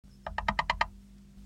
chant 1